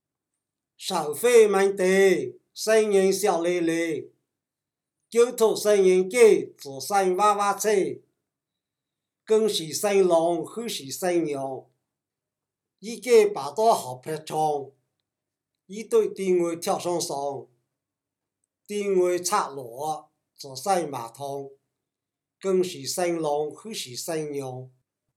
01手掀门帘